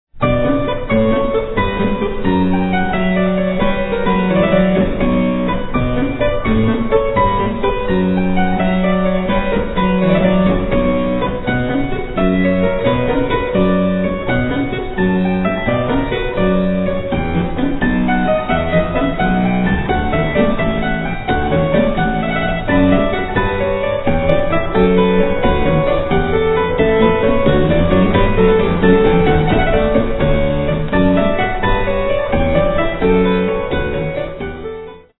harpsichordist
Sonata for keyboard in B minor, K. 409 (L. 150) - 4:29